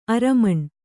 ♪ aramaṇ